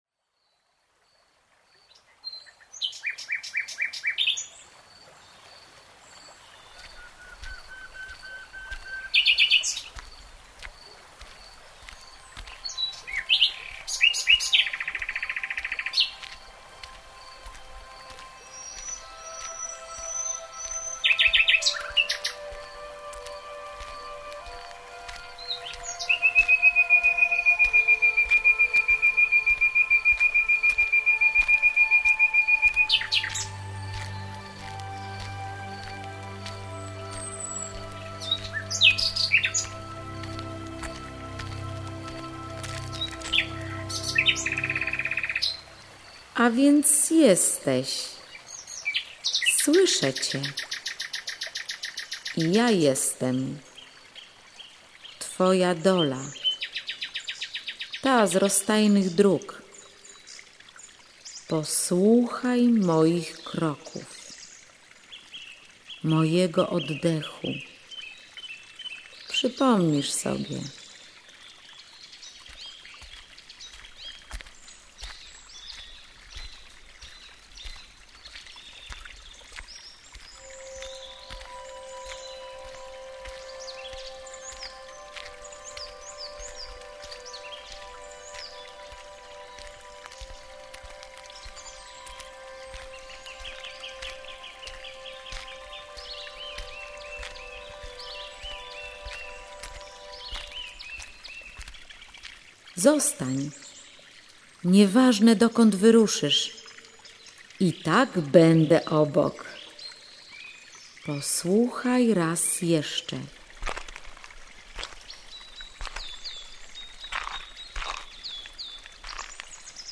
link do ścieżki dźwiękowej przedstawienia